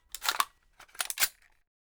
Bullet In 5.wav